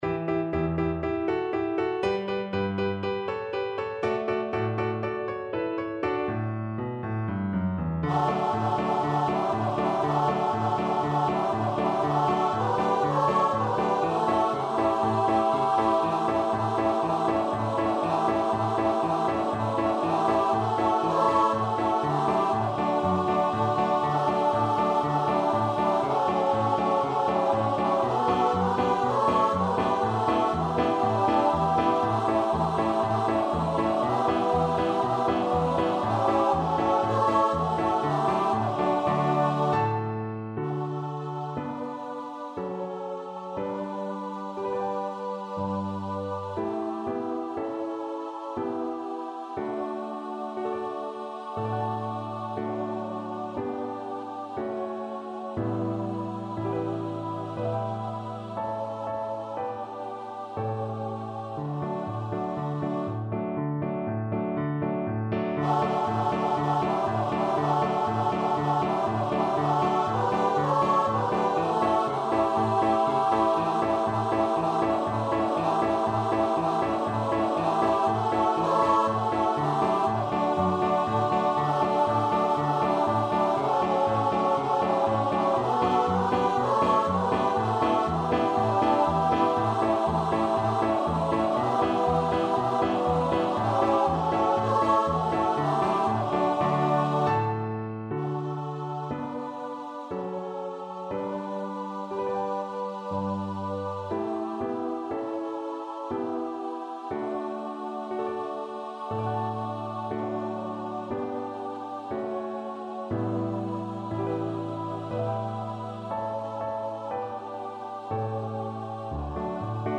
Free Sheet music for Choir (SAB)
SopranoAltoBass
4/4 (View more 4/4 Music)
Allegro (View more music marked Allegro)
Choir  (View more Easy Choir Music)
Classical (View more Classical Choir Music)